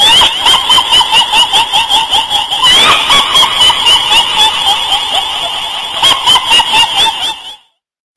MENANGIS